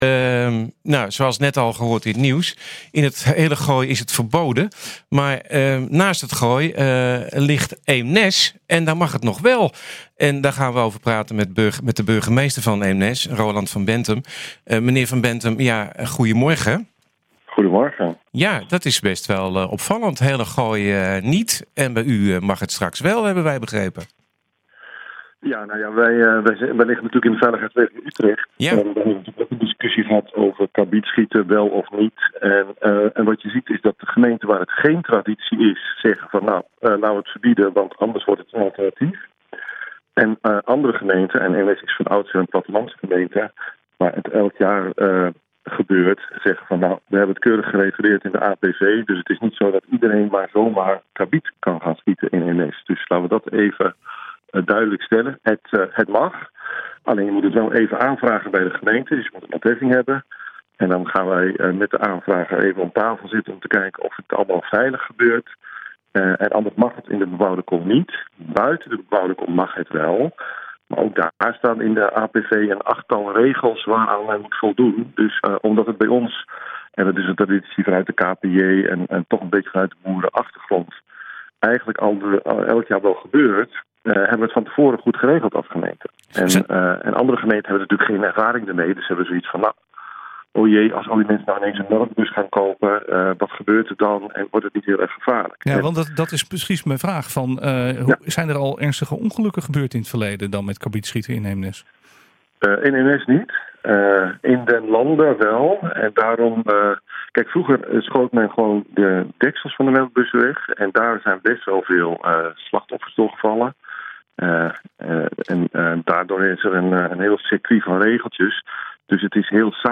De burgemeester van Eemnes, Roland van Benthem, benadrukt in het radioprogramma NH Gooi Zaterdag wel dat het veilig moet gebeuren.